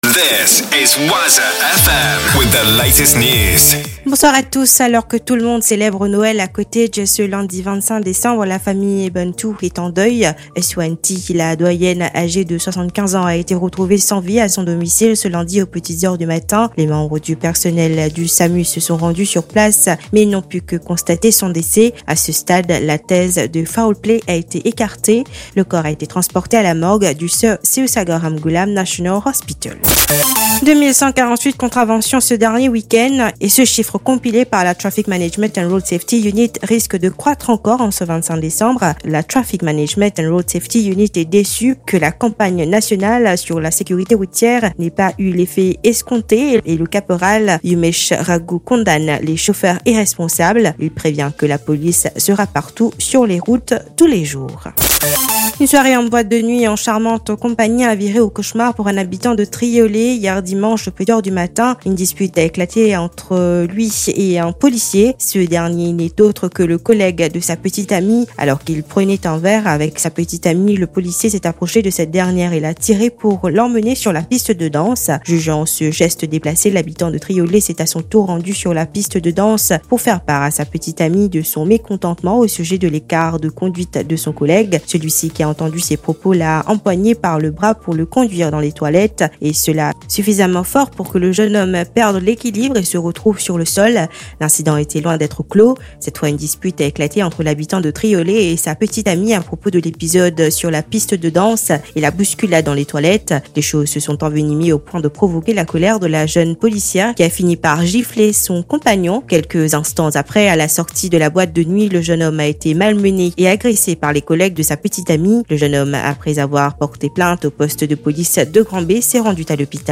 NEWS 17H - 25.12.23